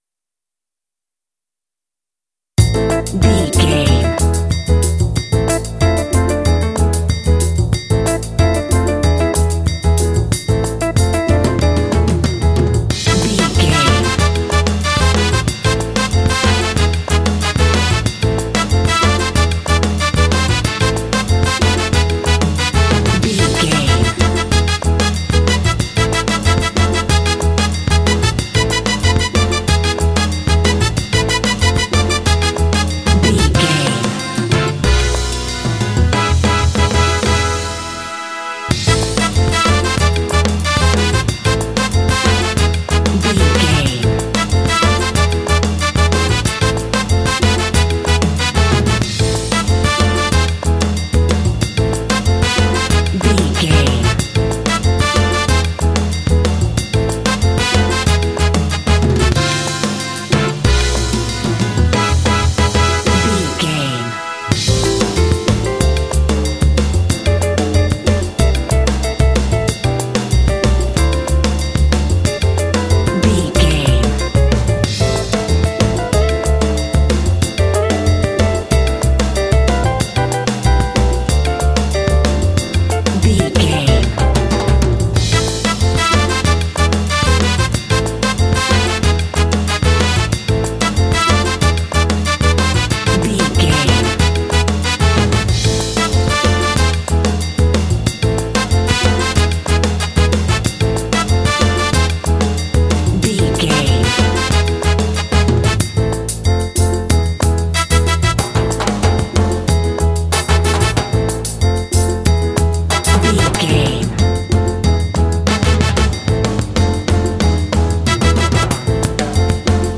Aeolian/Minor
smooth
percussion
bass guitar
trumpet
conga
Lounge
downtempo